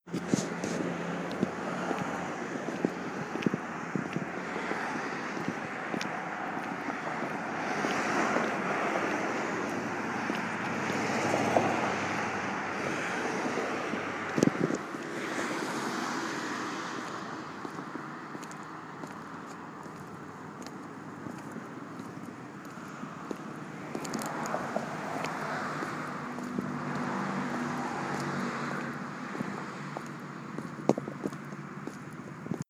Field Recording
3/3/16 8:07 pm Walking down Front St.
Sounds: cars passing by, heels walking on concrete, and wind.